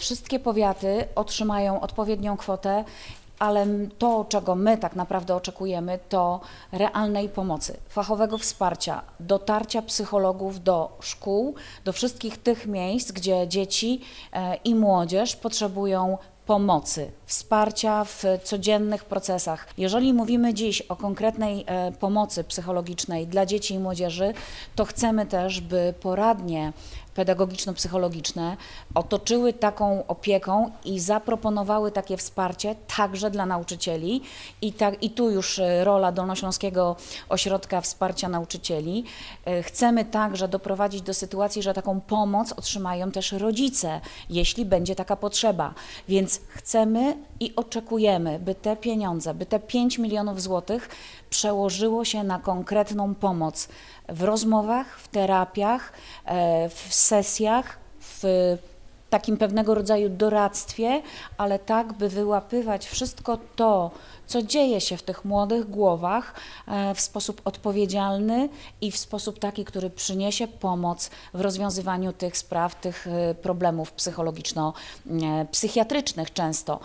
O szczegółach technicznych programu mówi jego autorka – Monika Włodarczyk, radna Województwa i przewodnicząca Komisji Kultury, Nauki i Edukacji.